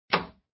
knock.mp3